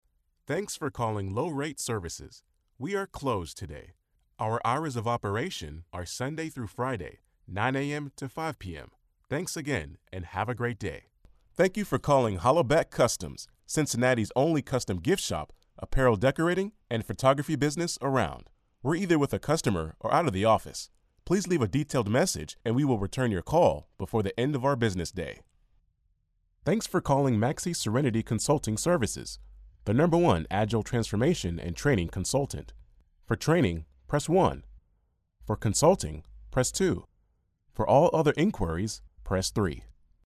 English (American)
Deep, Urban, Friendly
Telephony